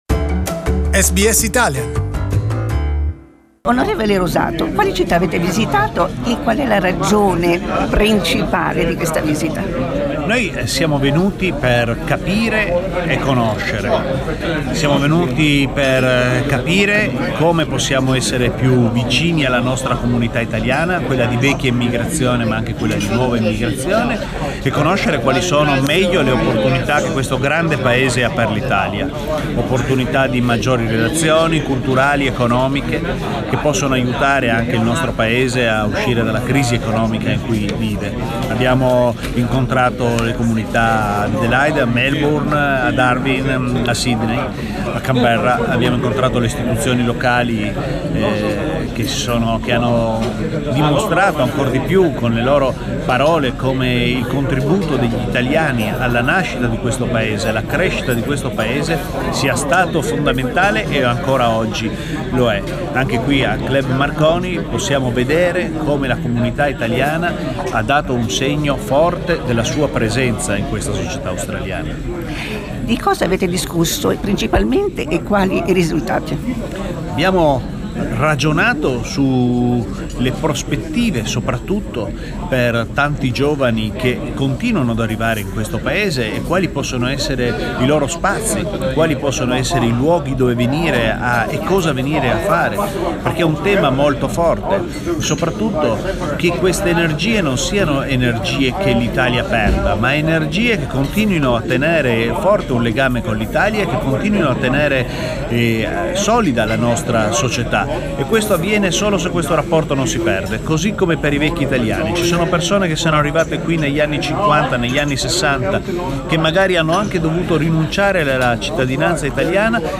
On the purpose of the visit, we interviewed Mr Rosato and Mr Carè.